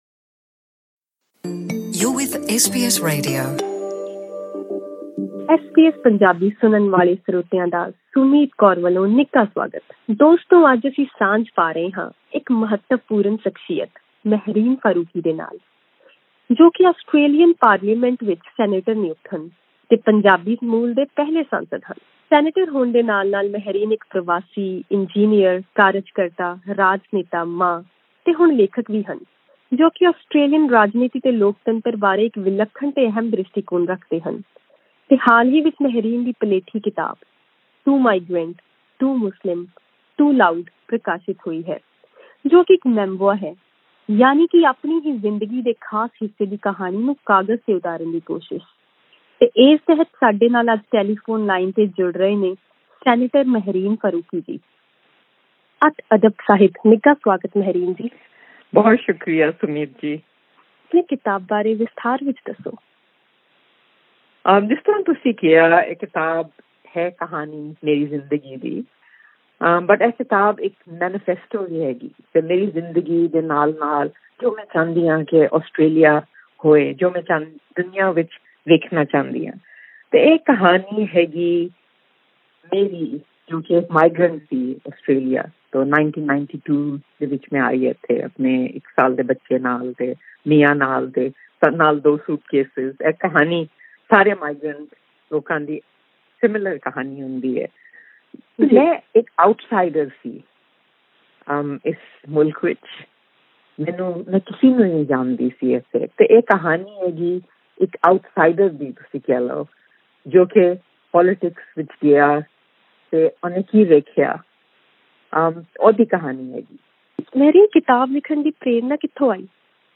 ਸੈਨੇਟਰ ਮਹਿਰੀਨ ਫਾਰੂਕੀ ਦੀ ਪਲੇਠੀ ਕਿਤਾਬ 'ਟੂ ਮਾਈਗ੍ਰੈਂਟ, ਟੂ ਮੁਸਲਿਮ, ਟੂ ਲਾਊਡ' ਪ੍ਰਕਾਸ਼ਿਤ ਹੋਈ ਹੈ ਜੋ ਕਿ ਉਨ੍ਹਾਂ ਦੀ ਆਪਣੀ ਜ਼ਿੰਦਗੀ ਅਤੇ ਆਸਟ੍ਰੇਲੀਅਨ ਰਾਜਨੀਤੀ ਵਿੱਚ ਇੱਕ ਪ੍ਰਵਾਸੀ ਹੋਣ ਦੇ ਨਾਤੇ ਵੱਖਰਾ ਮੁਕਾਮ ਬਨਾਉਣ ਦੇ ਮੁਸ਼ਕਿਲ ਸਫਰ ਉੱਤੇ ਅਧਾਰਿਤ ਹੈ। ਐਸ ਬੀ ਐਸ ਪੰਜਾਬੀ ਨੂੰ ਦਿੱਤੀ ਇਸ ਇੰਟਰਵਿਊ ਵਿੱਚ ਉਨ੍ਹਾਂ ਆਪਣੀ ਕਿਤਾਬ ਦੀ ਜਾਣਕਾਰੀ ਦੇ ਨਾਲ ਨਸਲਵਾਦ ਤੋਂ ਸੁਚੇਤ ਰਹਿਣ ਬਾਰੇ ਵੀ ਗੱਲਬਾਤ ਕੀਤੀ।